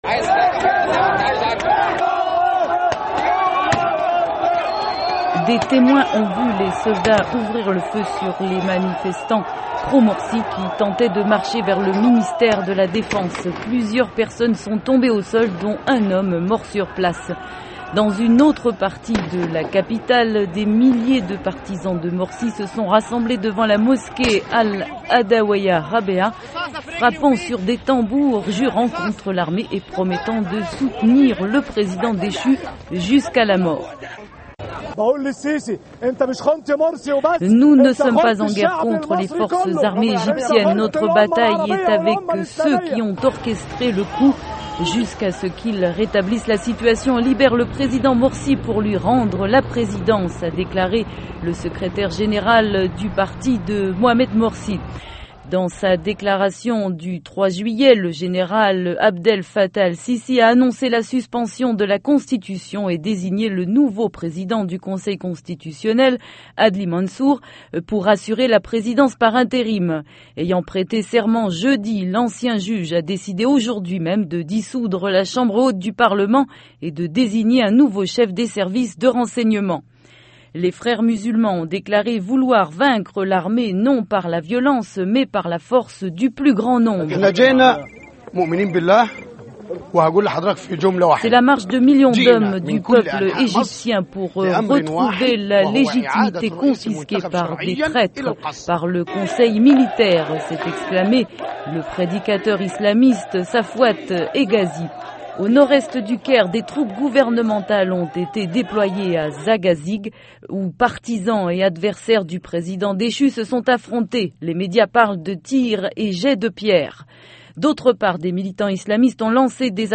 Ecoutez un reportage de la VOA